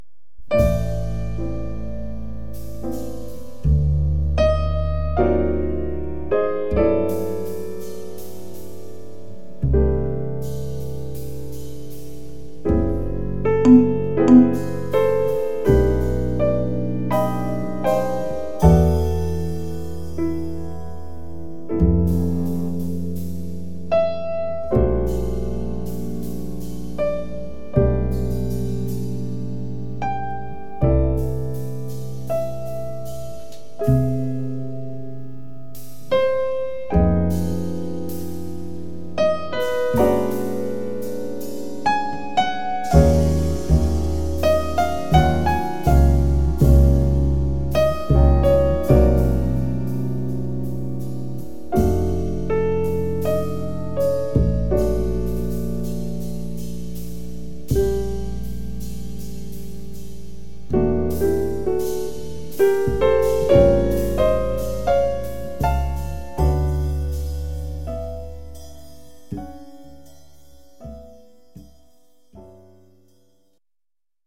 piano
contrabbasso
batteria